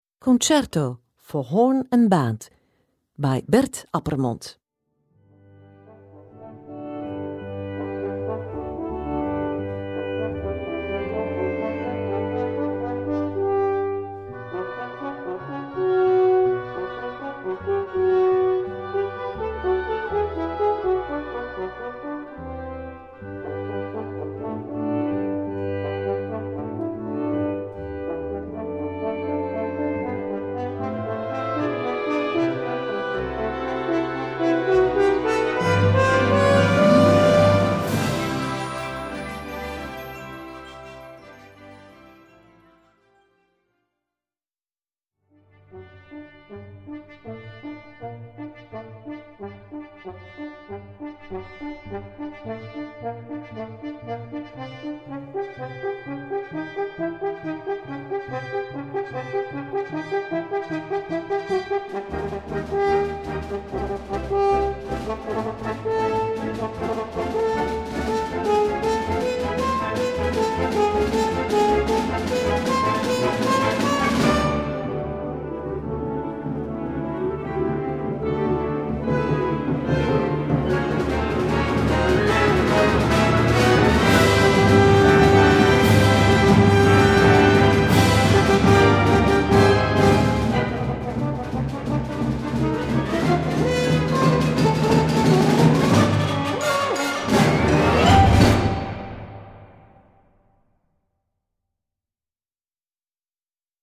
Gattung: für Horn Solo und Blasorchester
Besetzung: Blasorchester